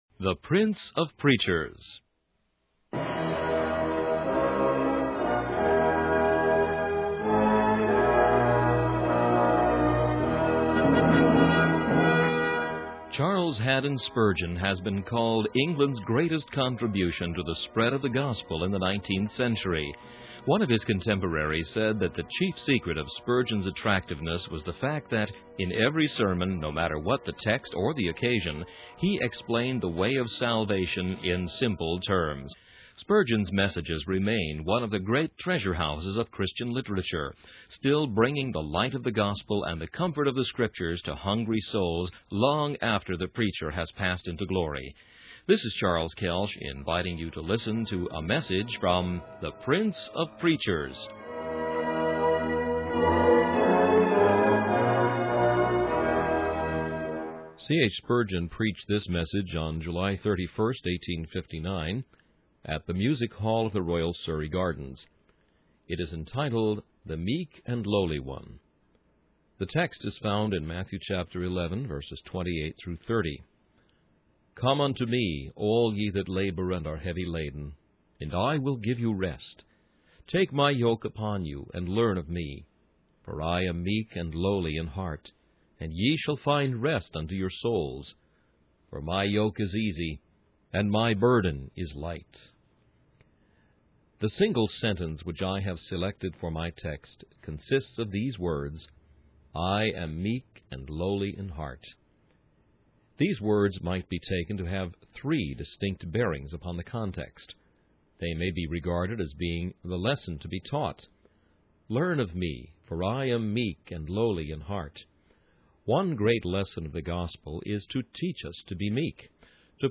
In this sermon, the preacher emphasizes the invitation of Jesus Christ to all who are rejected and despised, urging them to come and welcome him. He highlights that the only requirement for coming to Christ is to recognize one's need for him, which is a gift from the Holy Spirit.